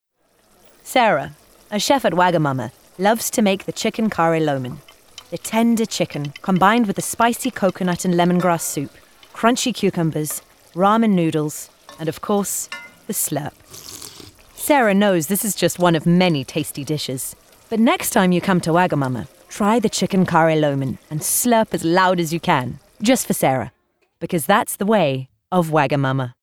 20/30's Neutral, Confident/Smooth/Clear